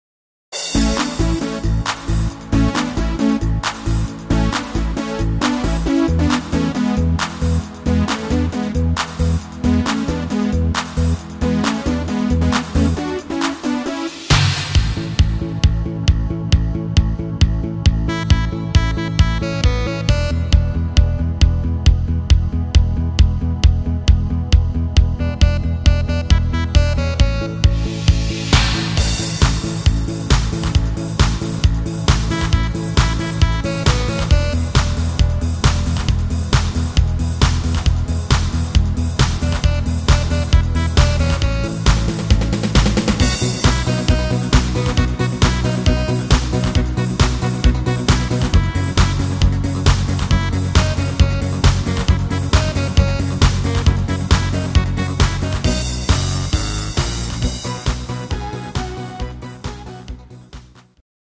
deftiger Mallorca Song